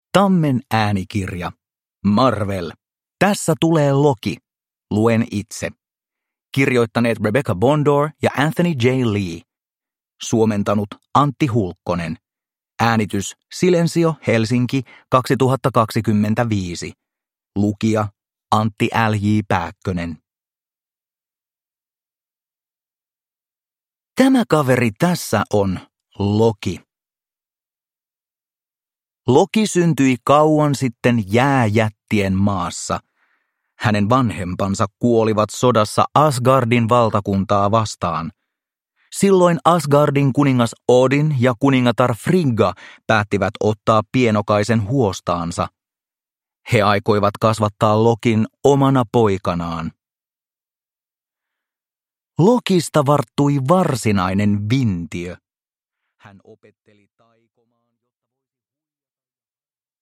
Tässä tulee Loki. Luen itse – Ljudbok
• Ljudbok